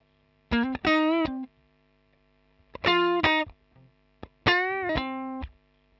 120_Guitar_funky_riff_C_3.wav